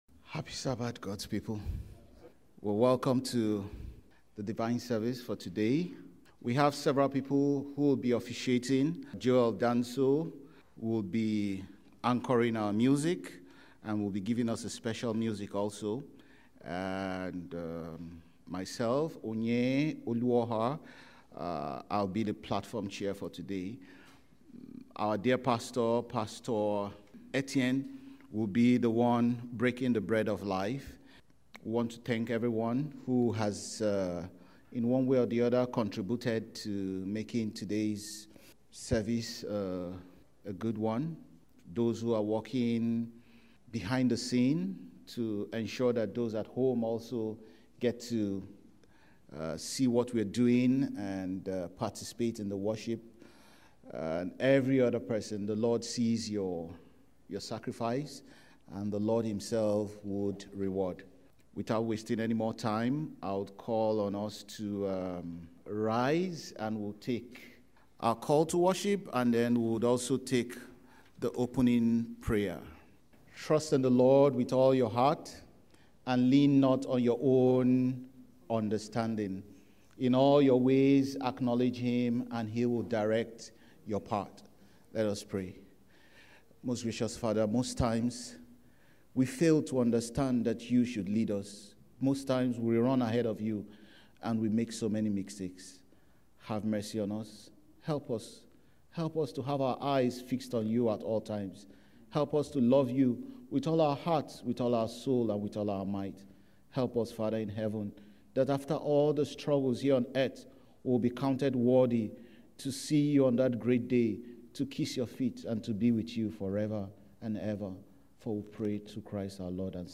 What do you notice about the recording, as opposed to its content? Sabbath service of the St. John's Seventh-day Adventist Church